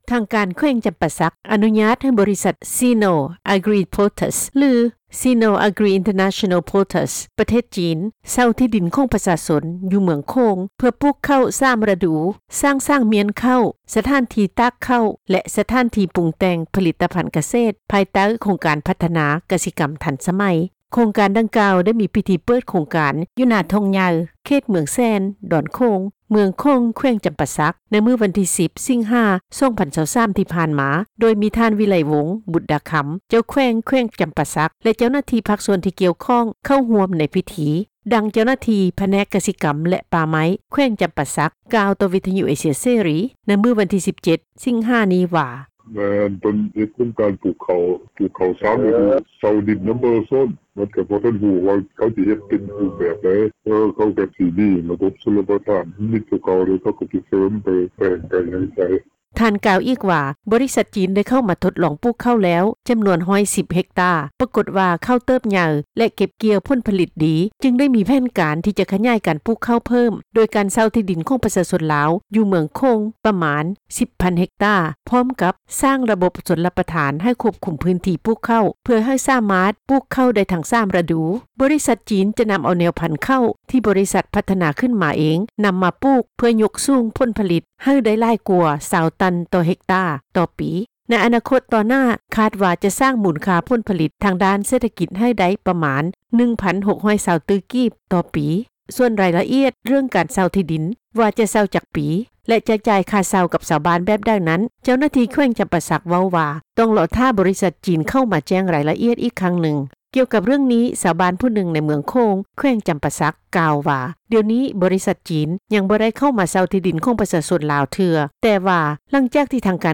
ດັ່ງ ເຈົ້າໜ້າທີ່ຜແນກ ກະສິກັມ ແລະ ປ່າໄມ້ ແຂວງຈໍາປາສັກ ກ່າວຕໍ່ວິທຍຸ ເອເຊັຽ ເສຣີ ໃນມື້ວັນທີ 17 ສິງຫາ ນີ້ວ່າ:
ດັ່ງ ຊາວລາວຜູ້ນີ້ ກ່າວຕໍ່ວິທຍຸ ເອເຊັຽ ເສຣີ ໃນມື້ດຽວກັນນີ້ວ່າ: